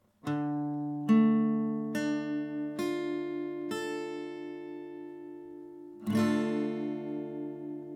d-Moll (Barré, A-Saite)
D-Moll, Barre A-Saite
D-Moll-Barre-A.mp3